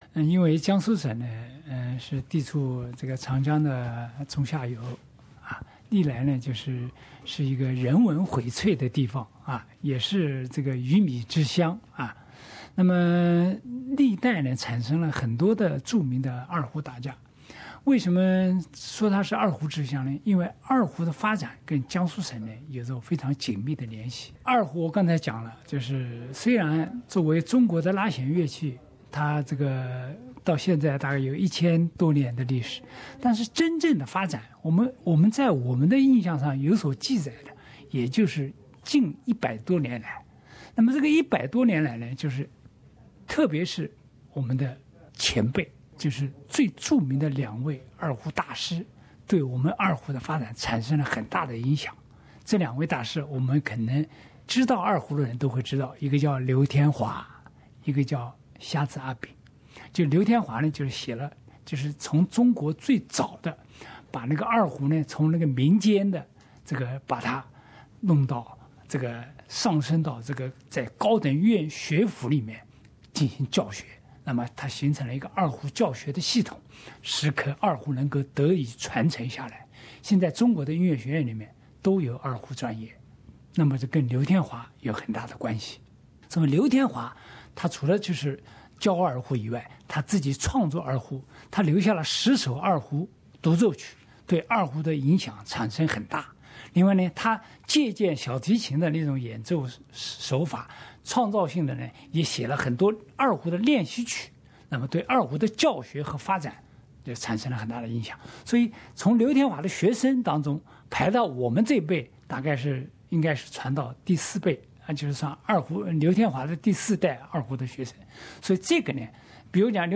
在他看来，江苏被称为"二胡之乡"，名至实归。请听详细采访录音。